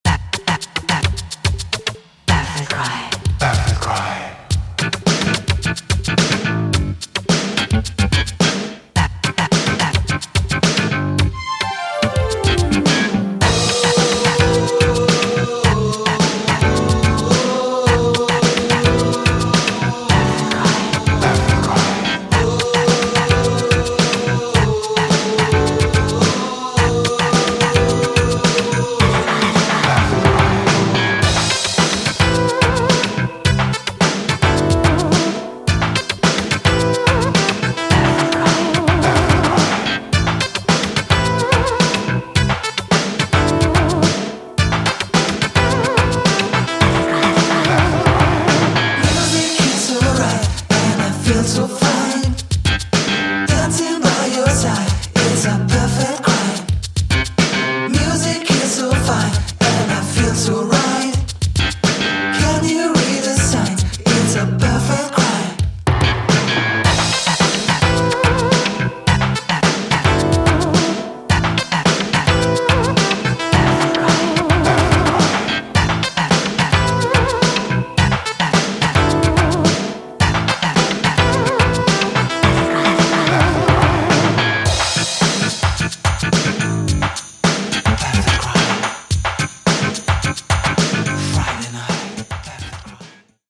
he presents a nostalgic vision of twilight synth-pop